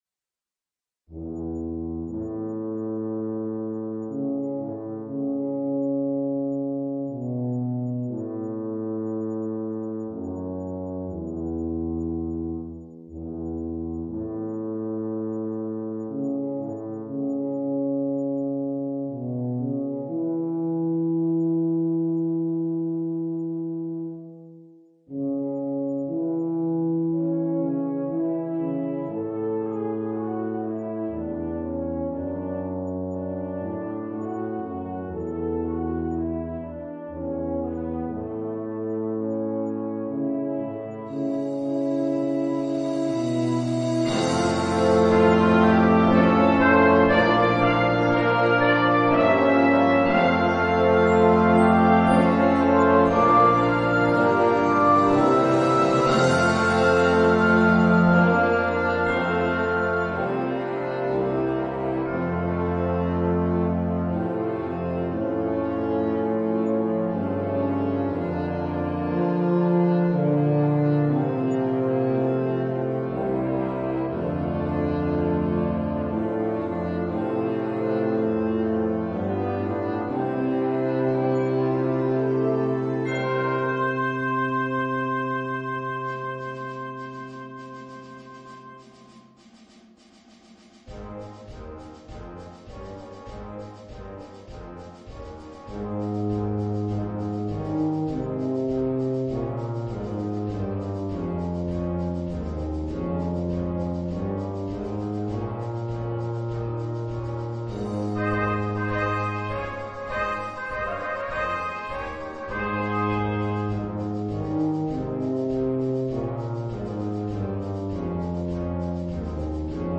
Voicing: Cnct Band